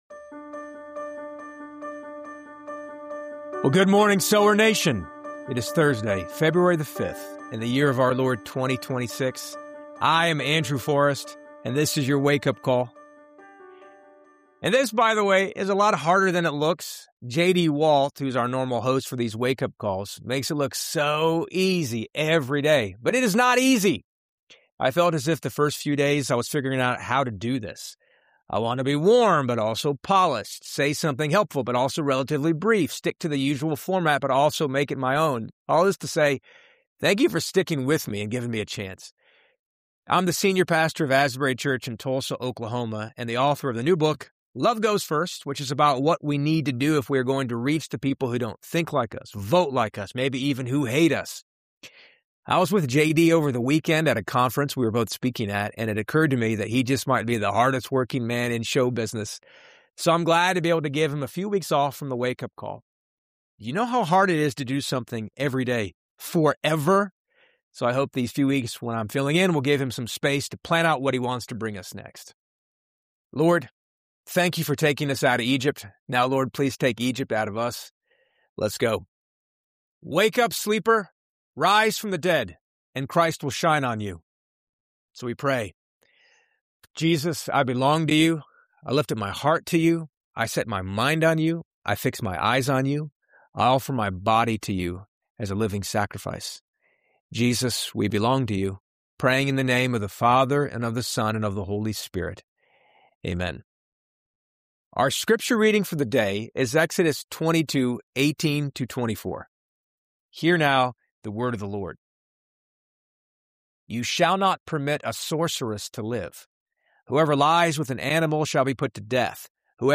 With warmth, honesty, and a touch of humor, he explores why the “weird laws” and detailed regulations in the latter half of Exodus might hold more beauty and relevance than we realize.
With heartfelt prayers, practical prompts, and the uplifting hymn “God of Grace and God of Glory,” this episode both comforts and challenges, inviting you to reexamine the foundations of your faith.